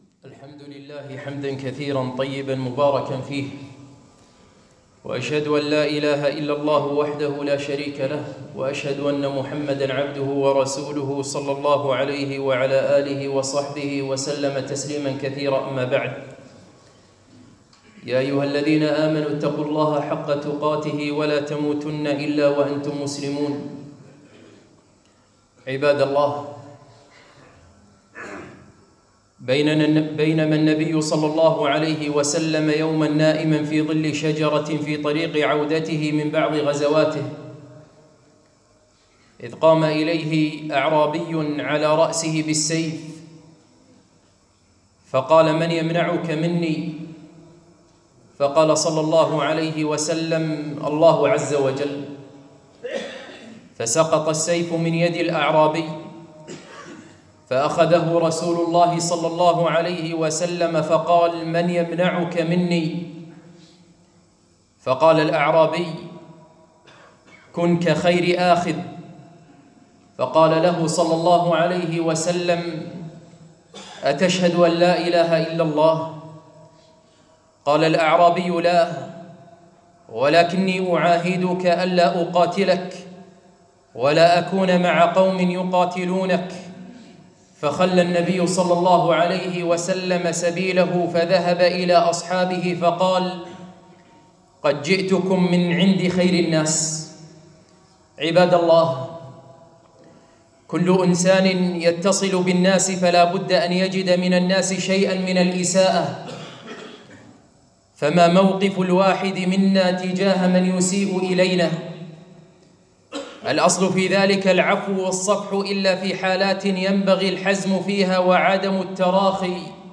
خطبة - كيف تعالج خصومات الآخرين؟ العفو عند المقدرة !!